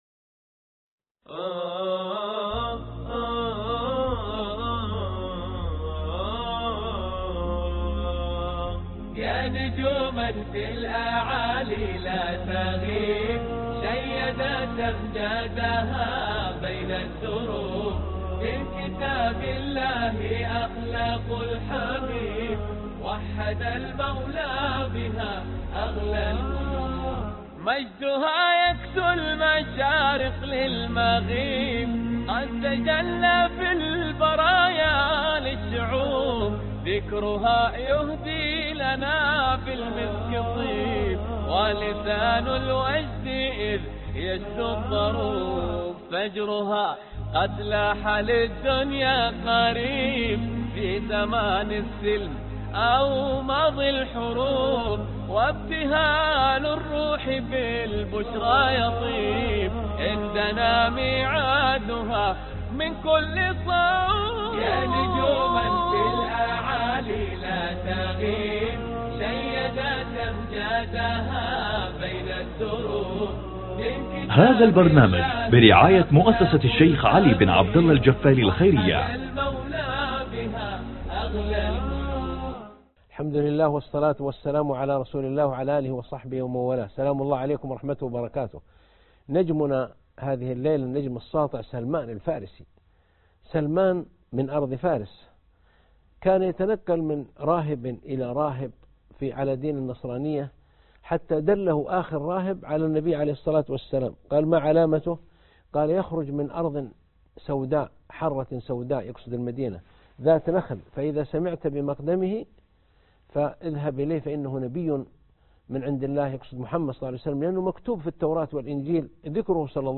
الدرس 17 (سلمان الفارسي رضي الله عنه) مع النجوم - الدكتور عائض القرنى